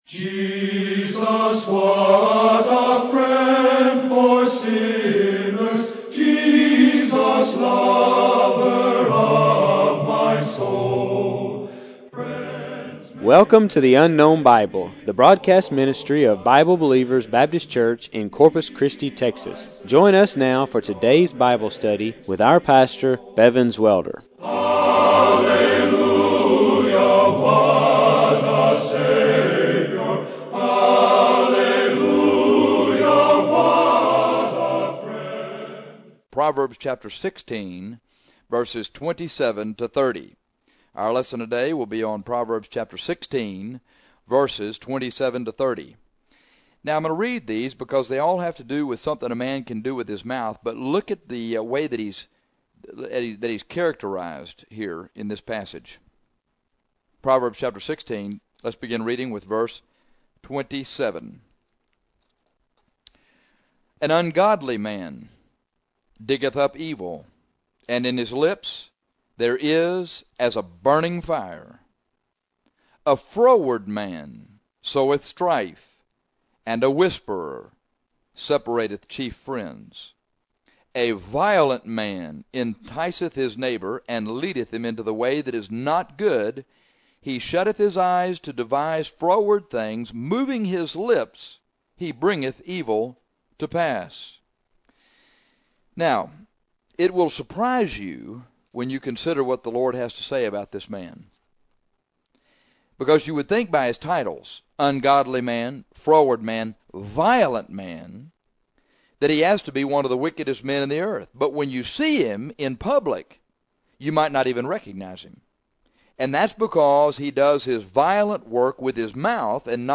This lesson is about an ungodly man. He would be unrecognizable by sight because he does his ungodly, froward, violent, dirty work with his mouth.